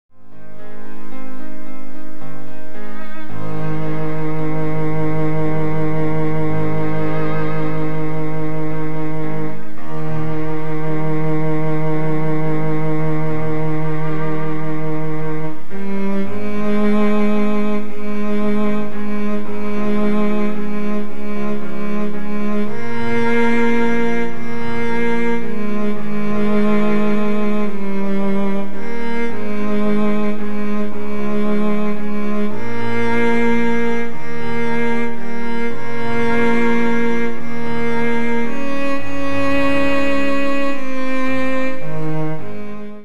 合唱 パート別・音取りＣＤ